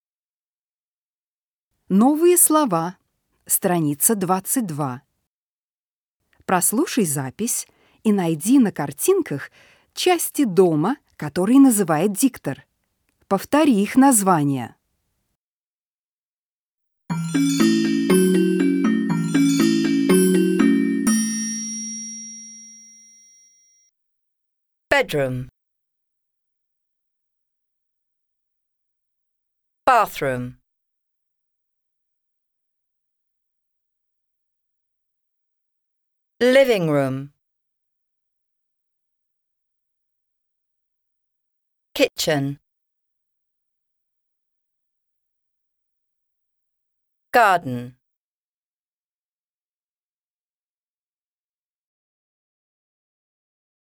Прослушай запись и найди на картинках части дома, которые называет диктор.
• bedroom – [бэдрум] – спальня
• bathroom – [бафрум] – ванная комната
• living room – [ливинг рум] – гостиная